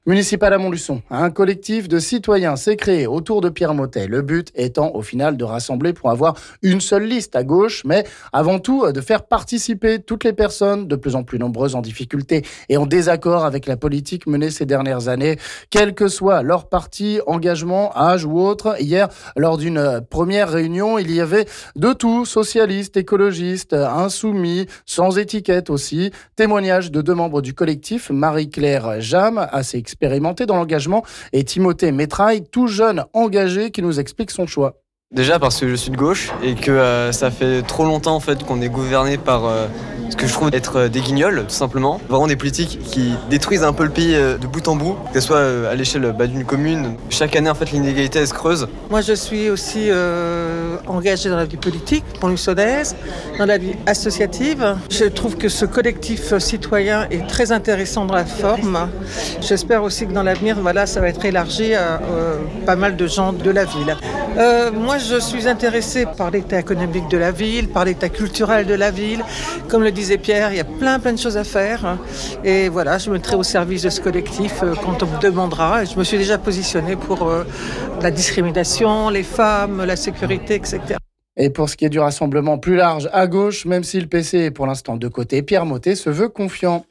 Témoignages de certains d'entre eux ici...